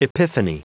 Prononciation du mot epiphany en anglais (fichier audio)
Prononciation du mot : epiphany